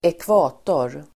Ladda ner uttalet
Uttal: [²ekv'a:tor]